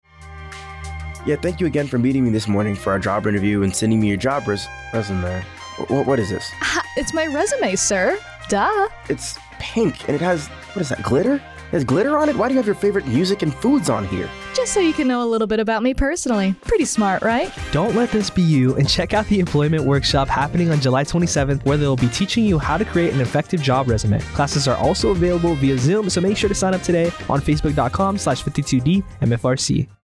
a 30 second spot on the Civilian Resume Workshop.